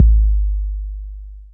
DB - Kick (9).wav